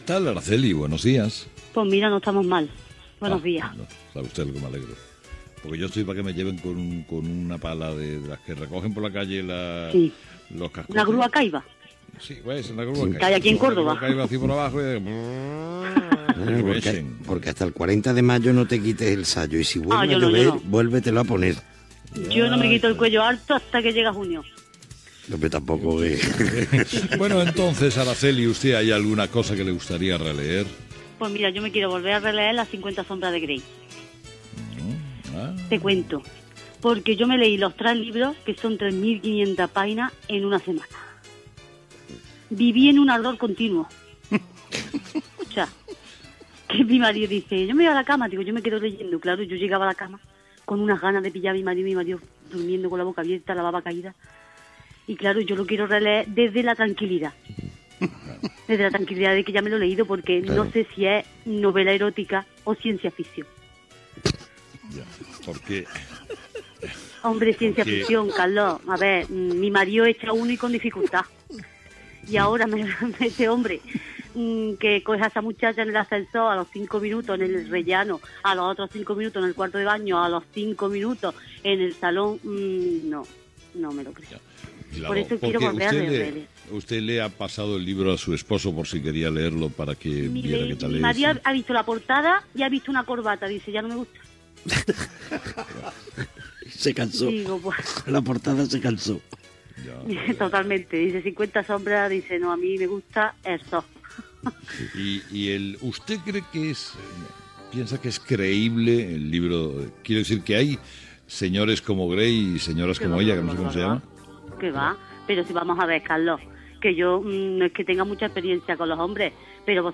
Fragment de la secció "La hora de los fósforos" amb una oient que havia llegit els llibres de la saga "50 sombres de Grey".
Info-entreteniment
FM